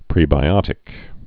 (prēbī-ŏtĭk)